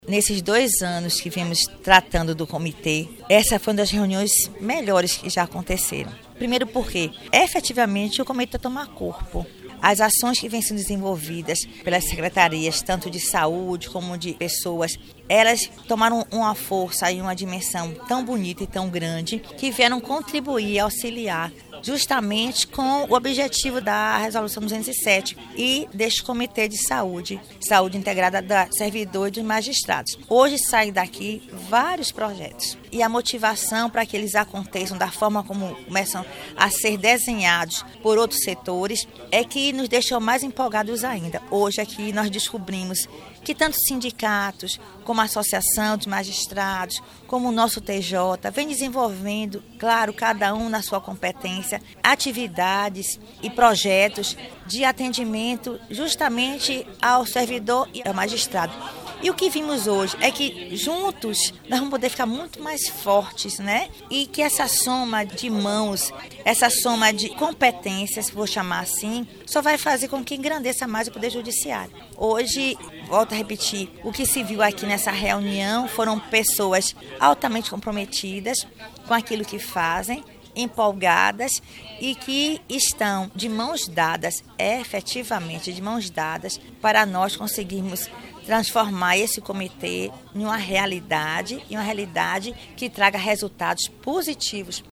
Desembargadora Pilar Célia Tobio de Claro
Presidente do Comitê Gestor Local de Atenção Integral à Saúde dos Magistrados e Servidores do Tribunal
Desa.-Pilar-Célia-Tobio-de-Claro-Presidente-do-Comitê-de-Saúde-Balanço-da-Reunião.mp3